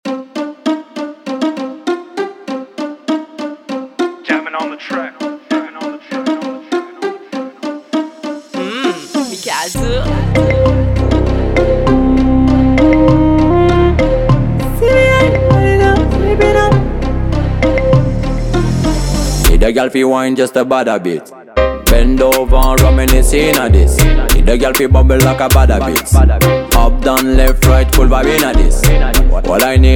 Жанр: Регги